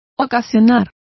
Complete with pronunciation of the translation of cause.